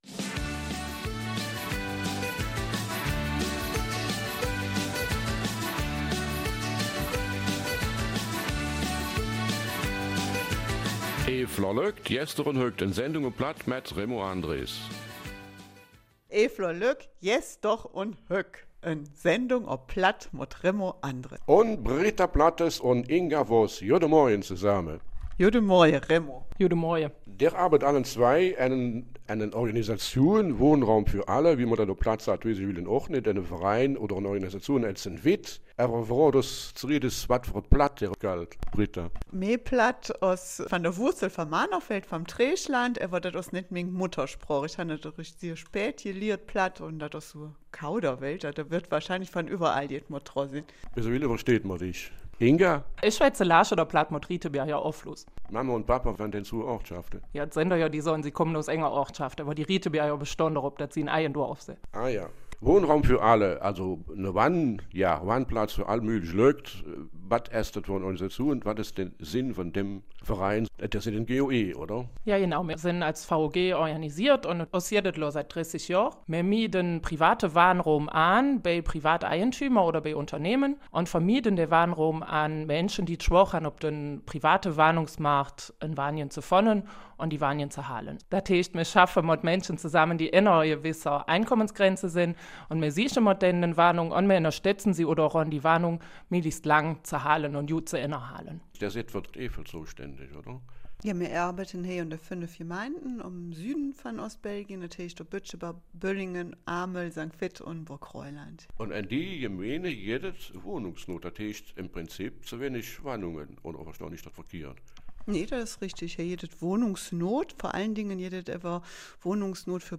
Eifeler Mundart - 7. Dezember